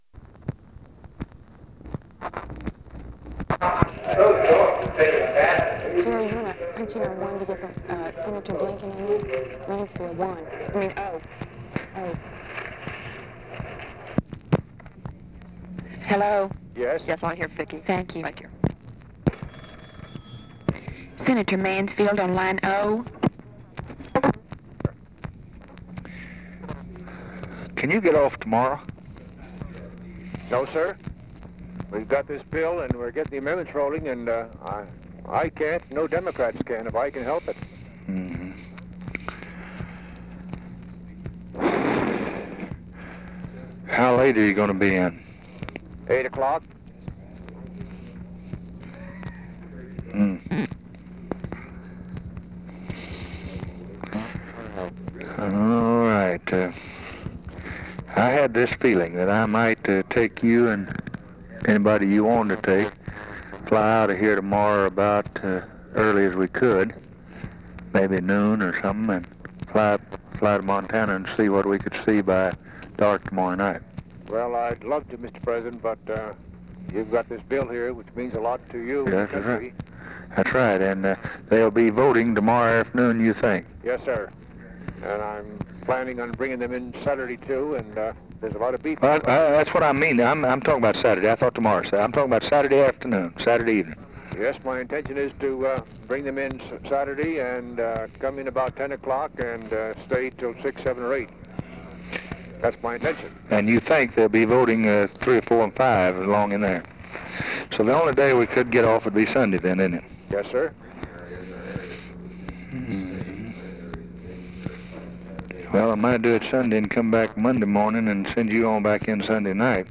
Conversation with MIKE MANSFIELD and OFFICE CONVERSATION, June 11, 1964
Secret White House Tapes